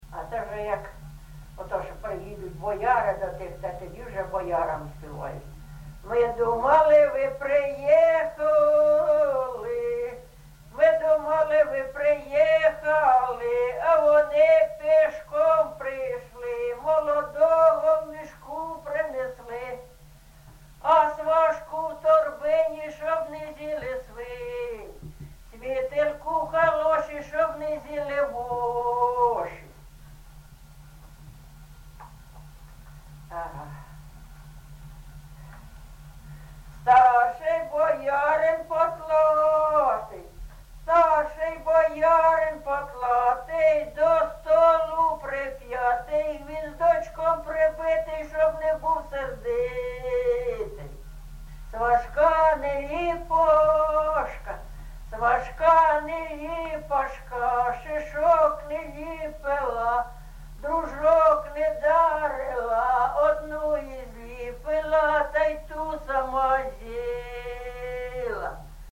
ЖанрВесільні
Місце записус. Софіївка, Краматорський район, Донецька обл., Україна, Слобожанщина